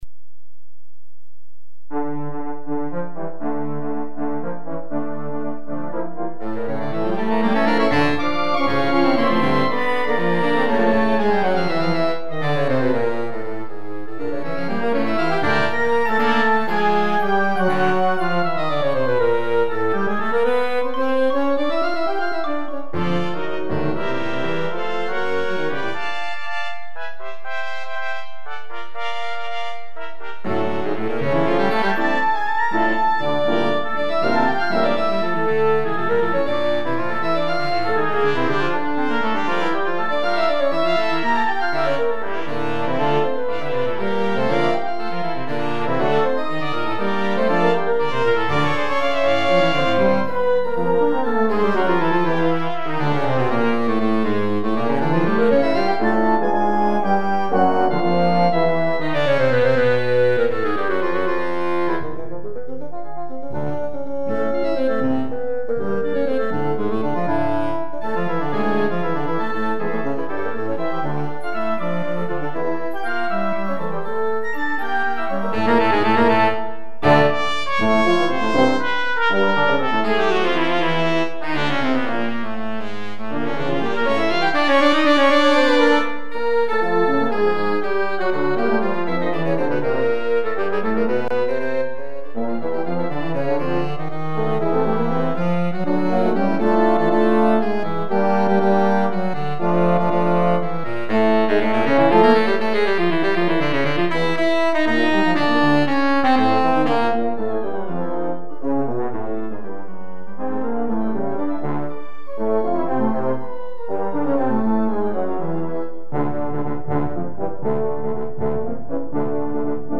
Wind Ensemble